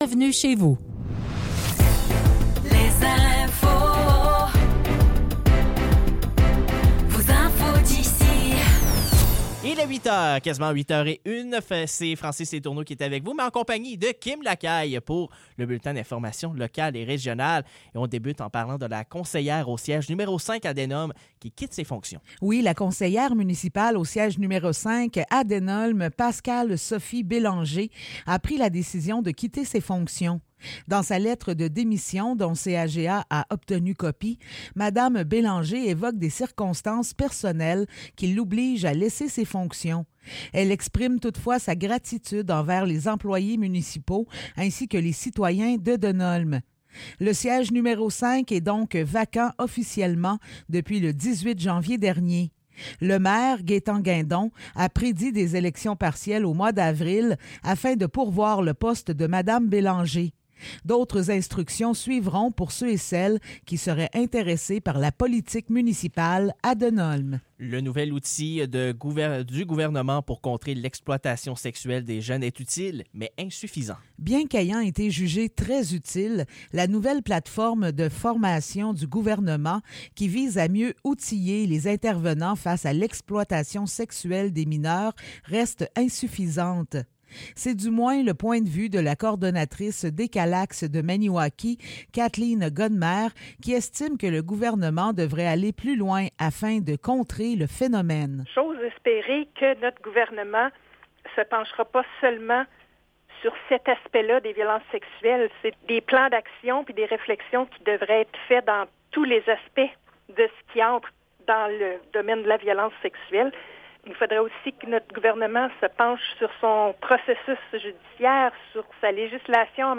Nouvelles locales - 13 février 2024 - 8 h